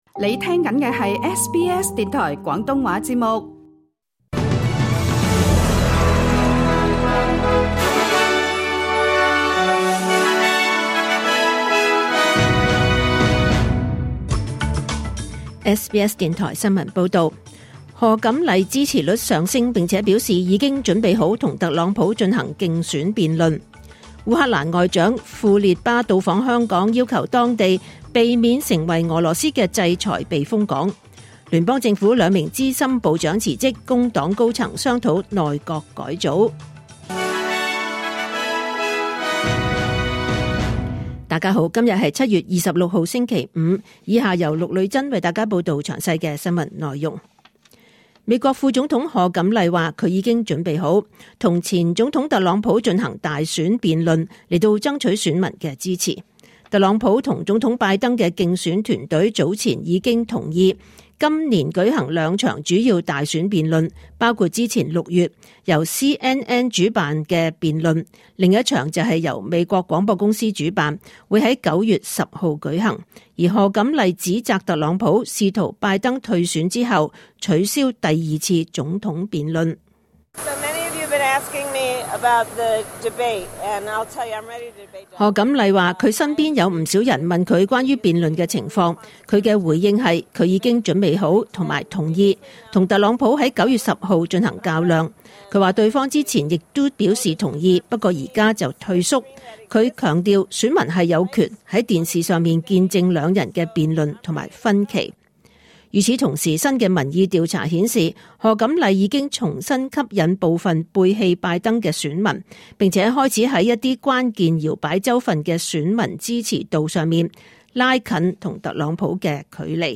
2024 年 7 月 26 日 SBS 廣東話節目詳盡早晨新聞報道。